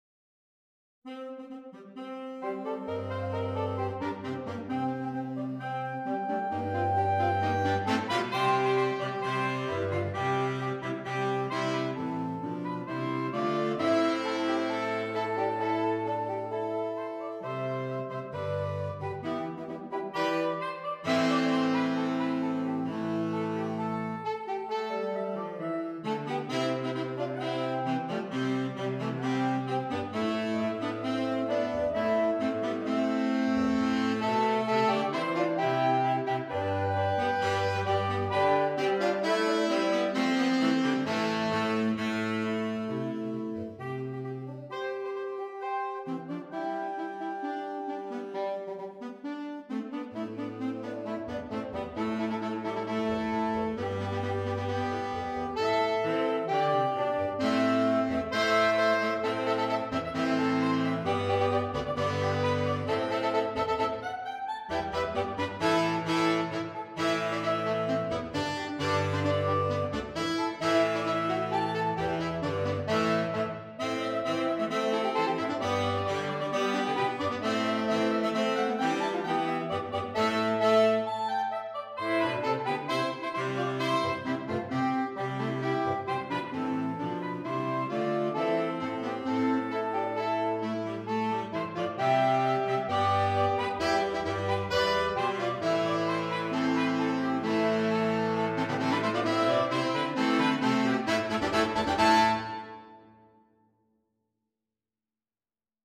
Saxophone Quartet (SATB)
Traditional